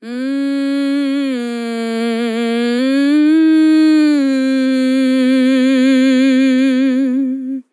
TEN VOCAL FILL 15 Sample
Categories: Vocals
dry, english, female, fill
POLI-VOCAL-Fills-100bpm-A-15.wav